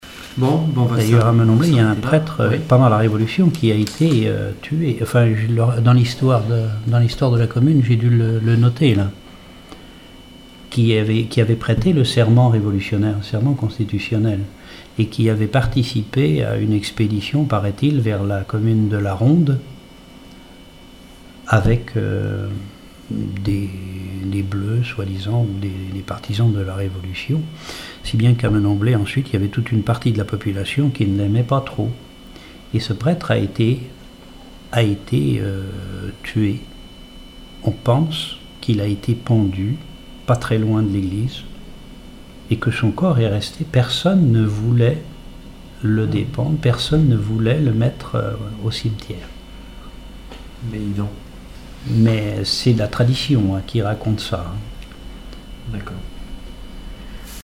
Témoignages ethnologiques et historiques
Catégorie Témoignage